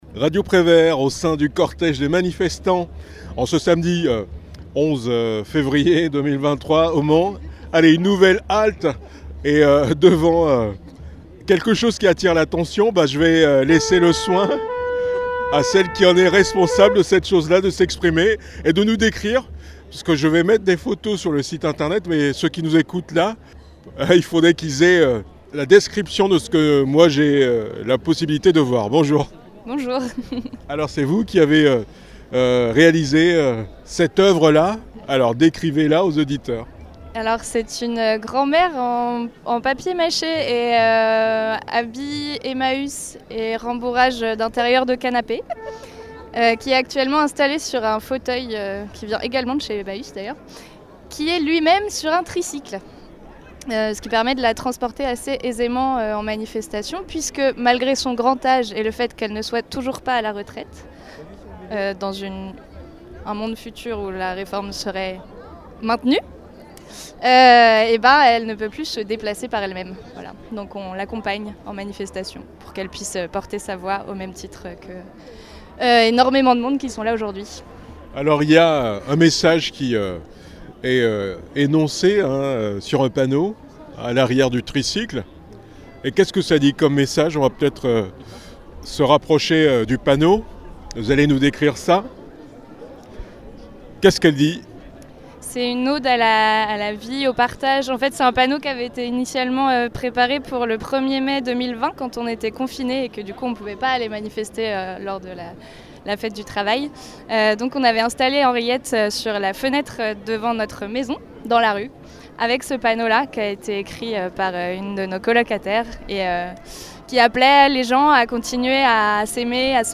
11 février 2023 : manifestation contre la réforme des retraites au Mans